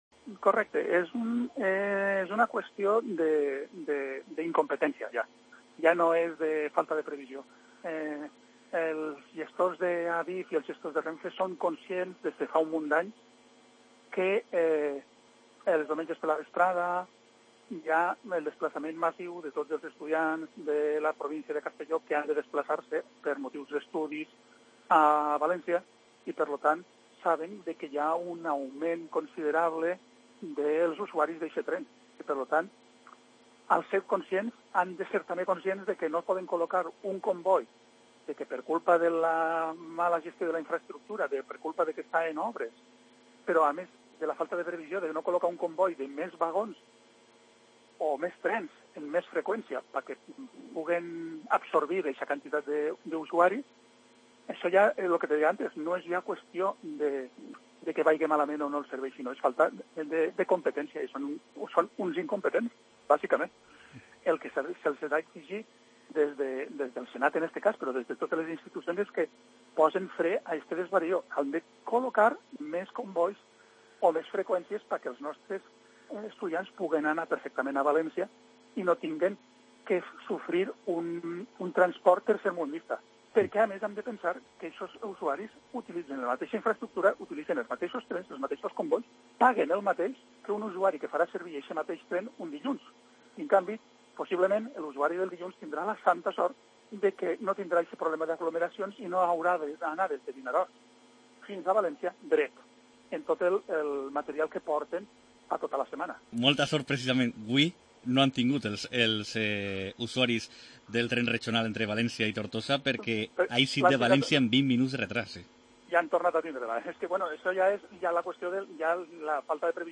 Entrevista a Jordi Navarrete